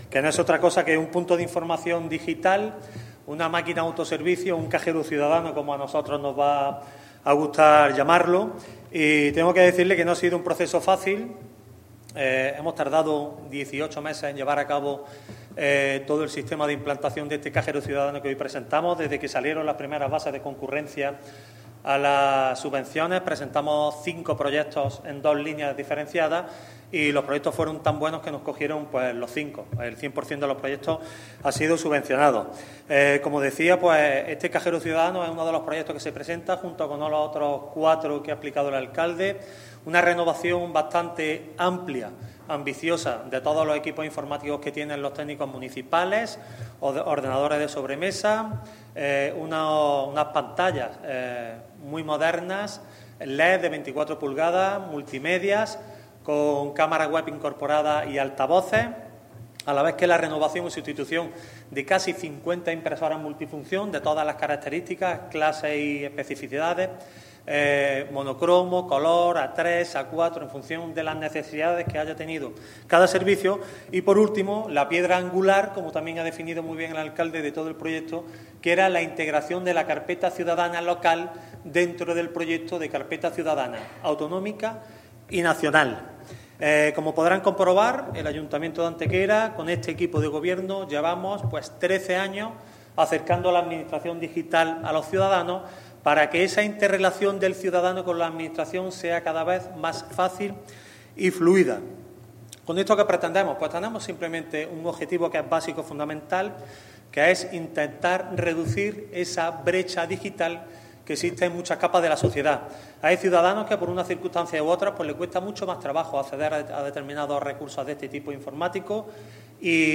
El alcalde de Antequera, Manolo Barón, y el teniente de alcalde delegado de Hacienda y Nuevas Tecnologías, Antonio García Acedo, han presentado ante los medios de comunicación el nuevo "Cajero Ciudadano", herramienta informática presencial ubicada en la planta baja del Ayuntamiento y que permite ya facilitar varios trámites administrativos.
Cortes de voz